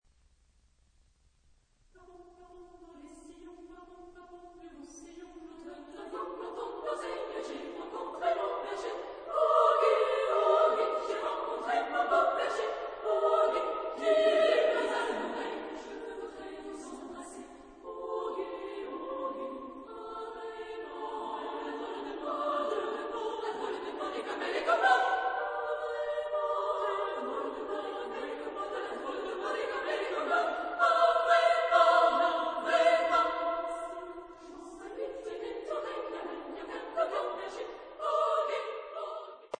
Genre-Style-Forme : contemporain ; Chanson ; Profane
Tonalité : sol majeur